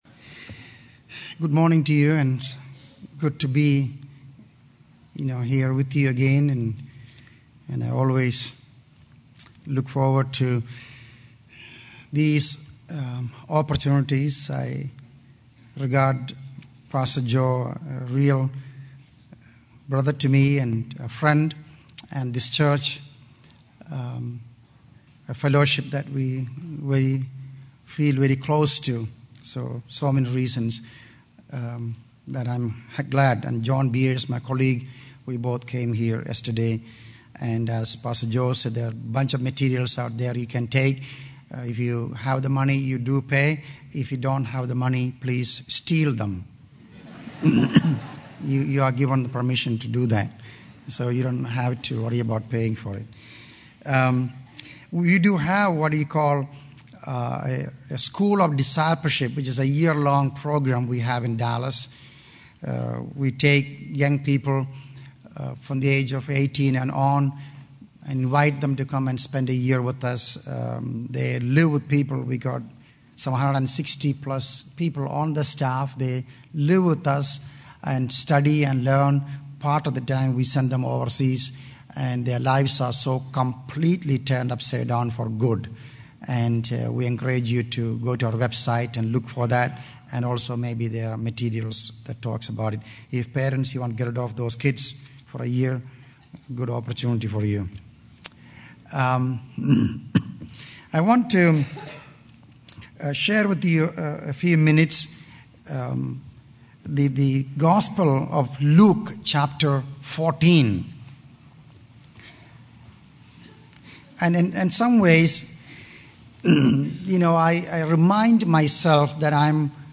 In this sermon, the speaker addresses the American church's crisis of preaching a cheap gospel and promoting a soft savior. He emphasizes the need for personal sacrifice, suppression from the world, and a hatred for sin in order to truly experience a new life in Christ. The speaker references Luke 14:25-33, where Jesus speaks about the requirements of discipleship, including hating one's own life and giving up everything to follow Him.